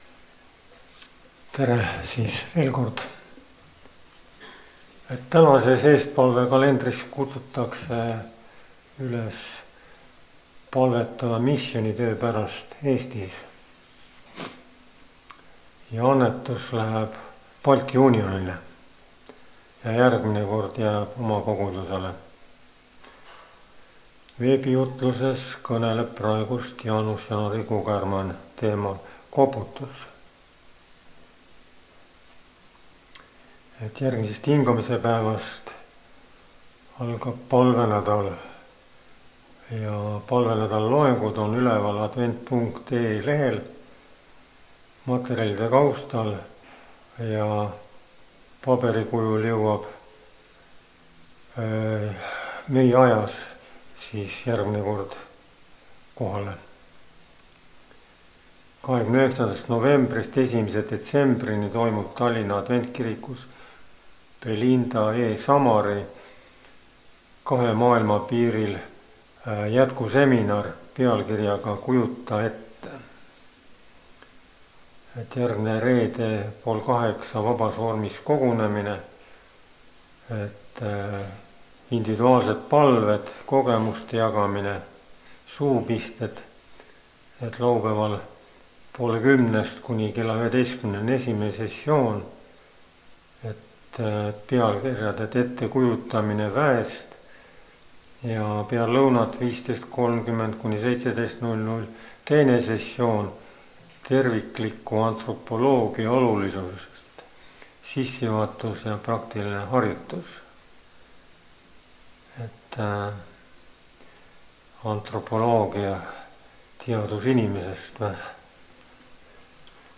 kategooria Audio / Koosolekute helisalvestused